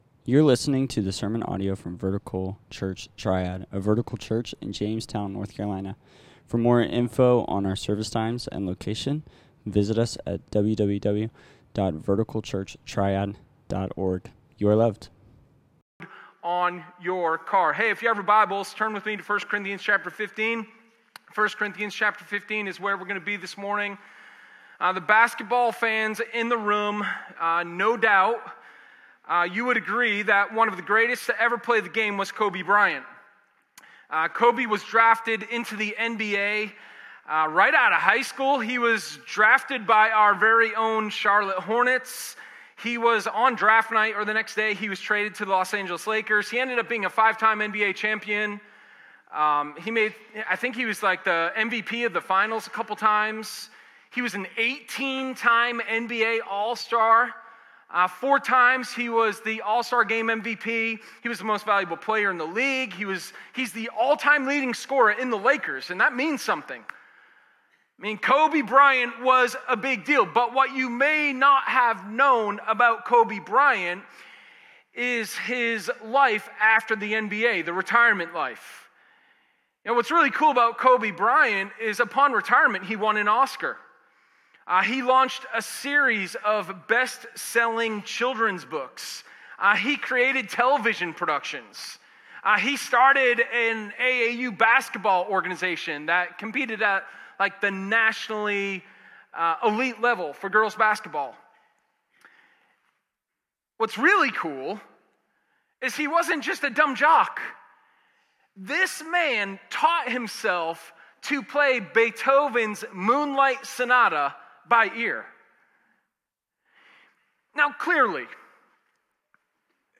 Sermon1106_New-Life-Mentality.m4a